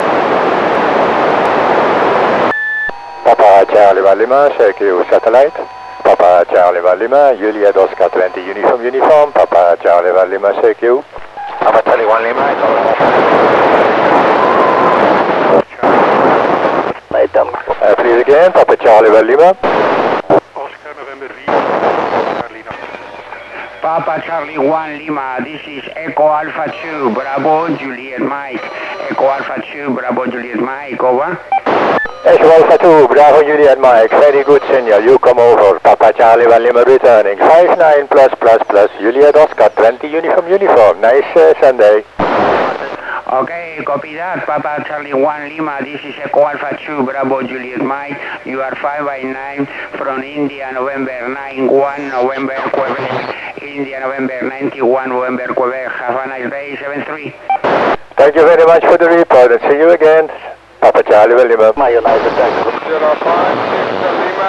Het vermogen is wel iets minder dan bv bij NA1SS ( ISS ) maar voldoende sterk om te kunnen ontvangen zonder voorversterker.